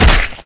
Damage3.ogg